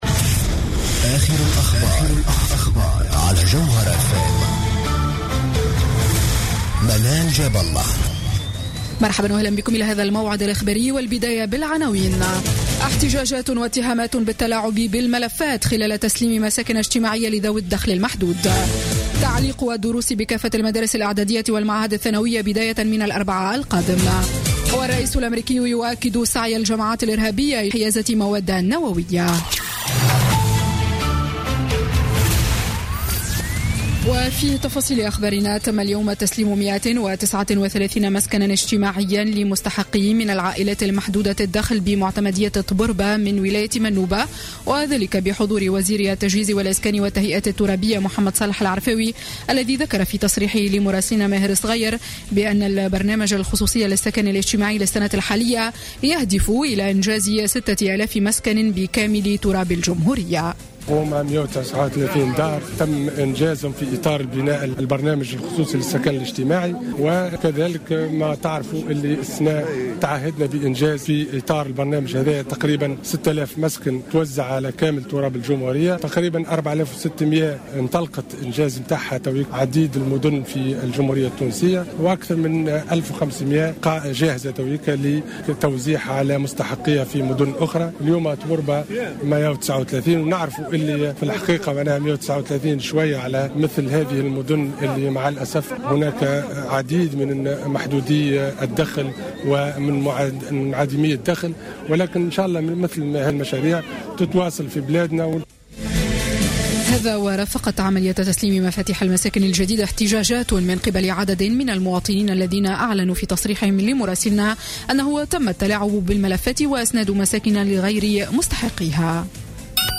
Journal Info 19h00 du Vendredi 1er Avril 2016